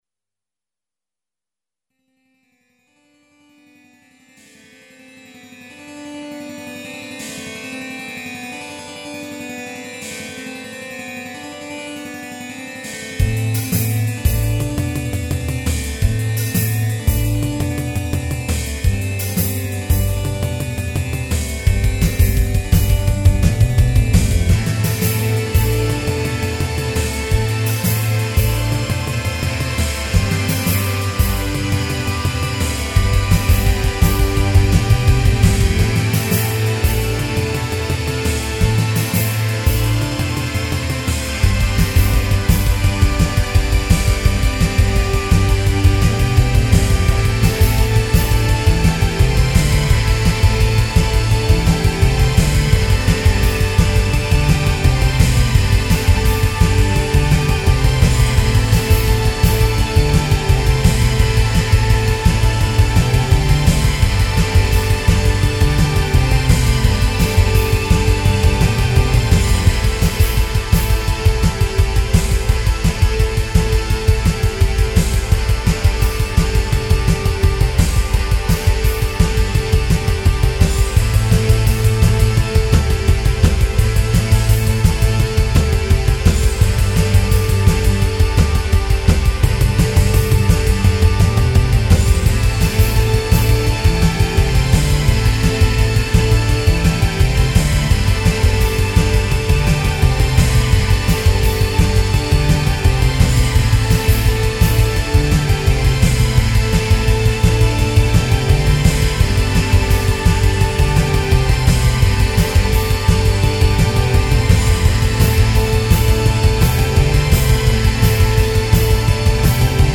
OPiated Rock
'05 Remix EQ